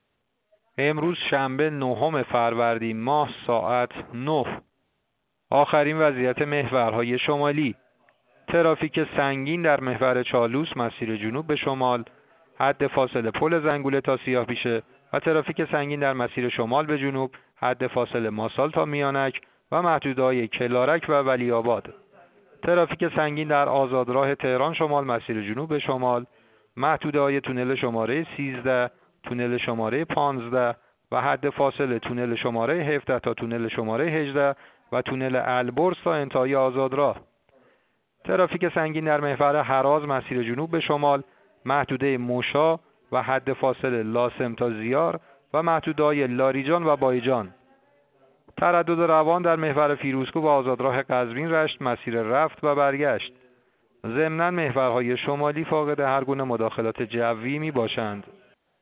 گزارش رادیو اینترنتی از آخرین وضعیت ترافیکی جاده‌ها ساعت ۹ نهم فروردین؛